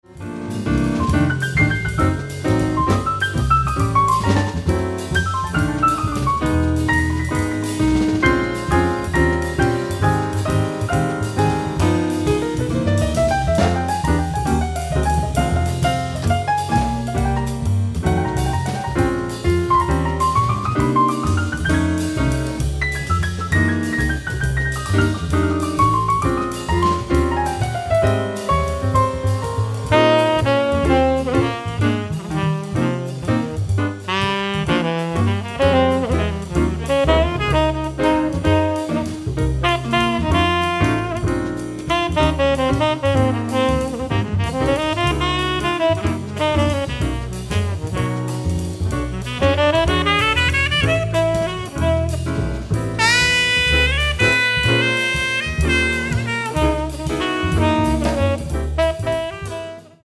piano
tenor saxophone
acoustic bass
drums